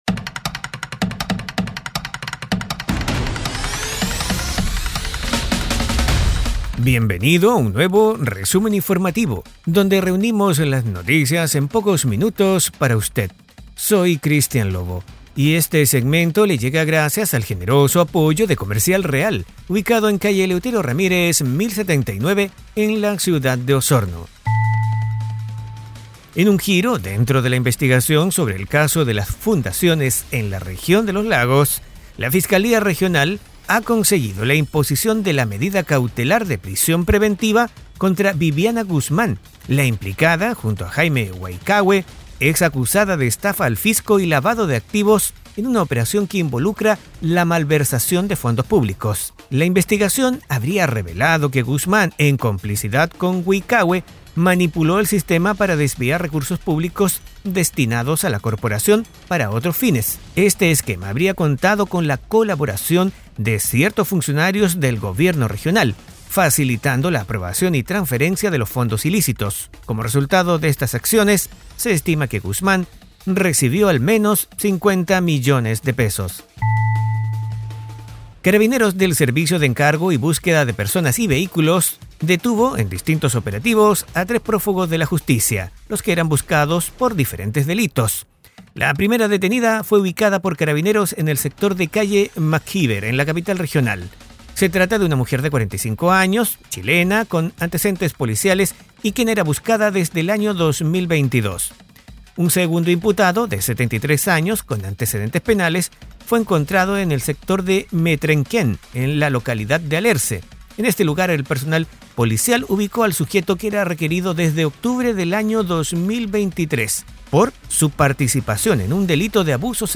Resumen Informativo 🎙 Podcast 14 de marzo de 2024